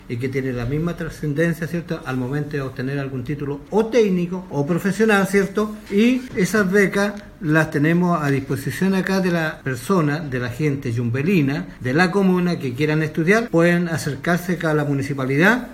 En tanto el alcalde Juan Cabezas, explicó que se estará apoyando con becas para suplir en un porcentaje a la hora de pagar la matrícula.